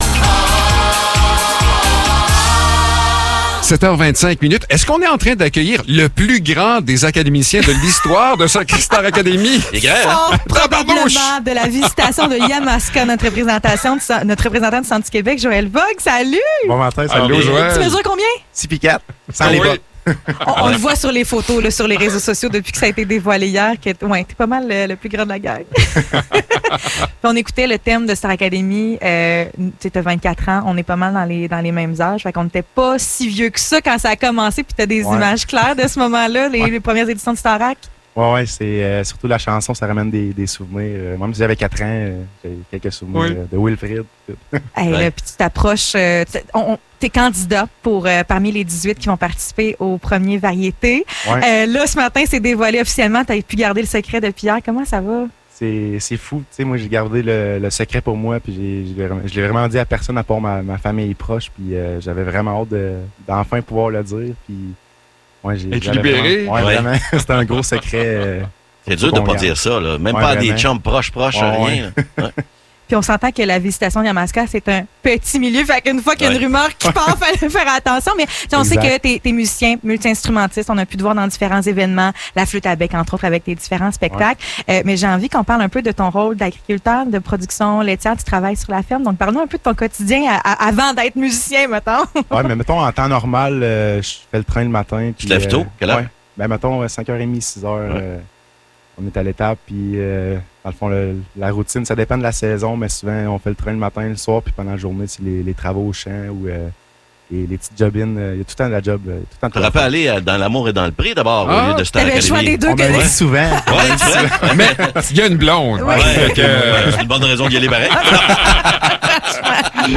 Entrevue
est passé dans nos studios pour nous parler de Star Académie